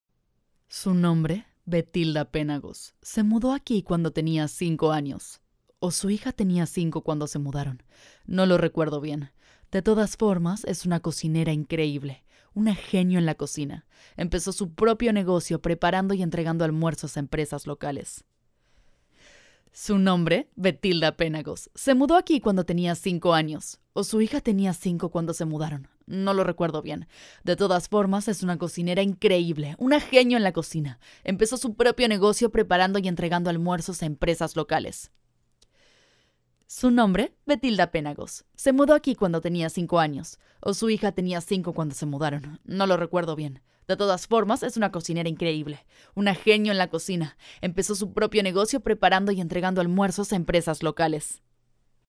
English voice over. Spanish accent